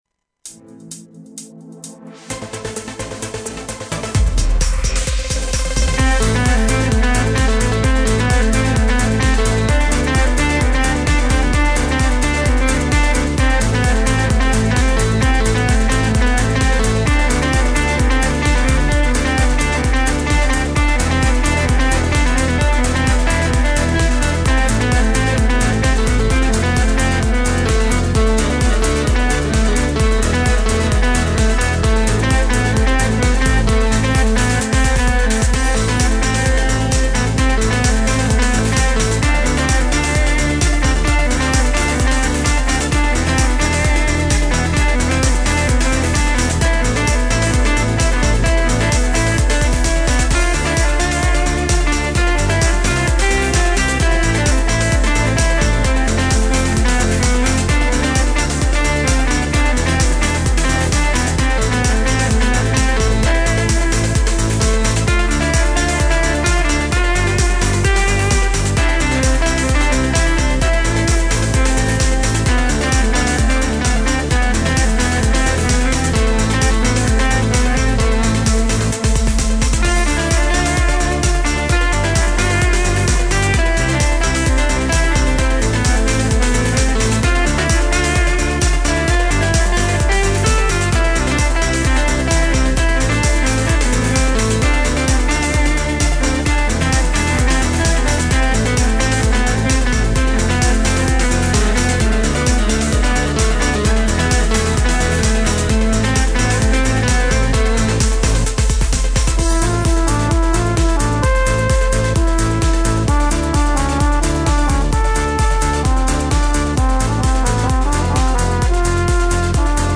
• Жанр: Поп
поп